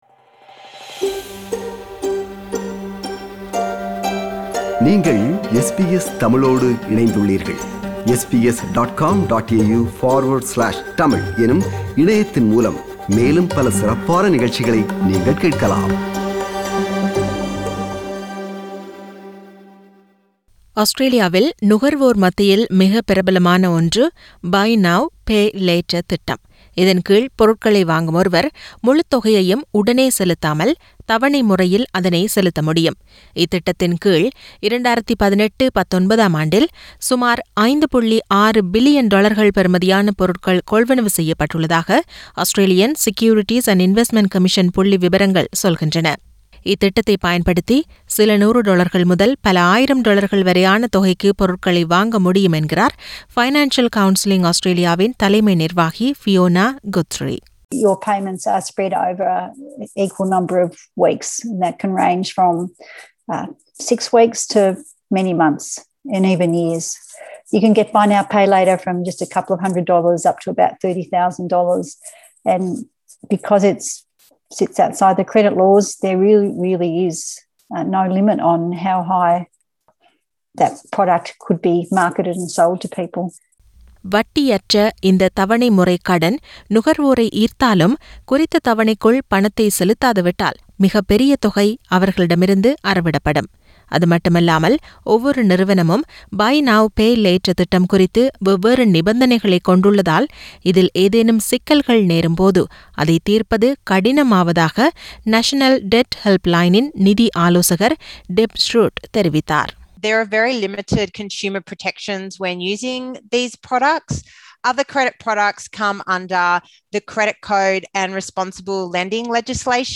இதன்கீழ் பொருட்களை வாங்கும் ஒருவர் முழுத்தொகையையும் உடனே செலுத்தாமல் தவணைமுறையில் அதனைச் செலுத்த முடியும். இத்திட்டத்தின்கீழ் உள்ள பல ஆபத்துக்களை விளக்கும் விவரணம்.